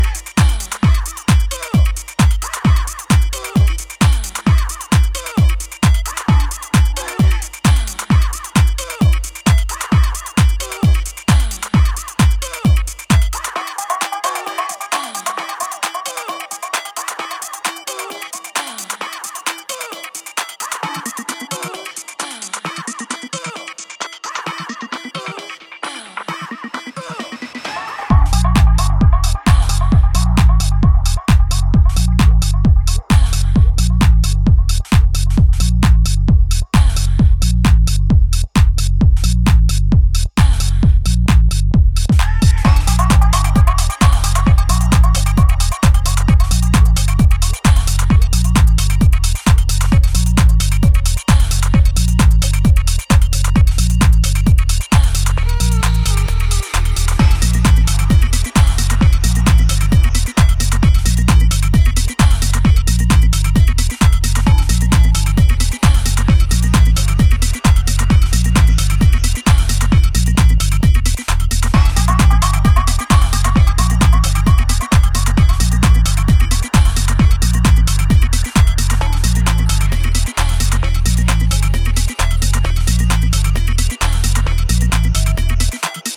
techno. With pulsating beats and intricate soundscapes